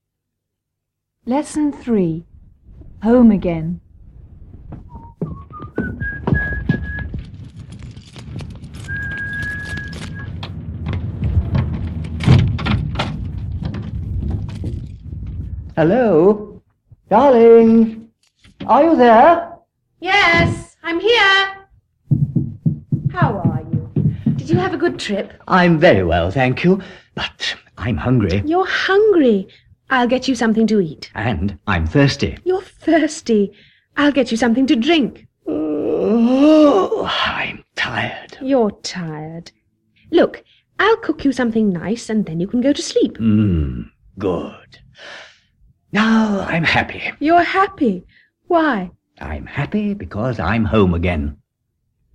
Dialogo: I'm happy, You're happy. Why?